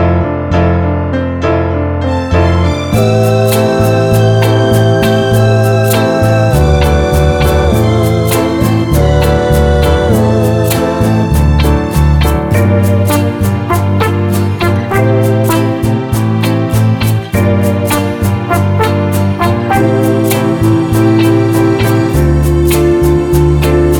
no Backing Vocals Soul / Motown 2:57 Buy £1.50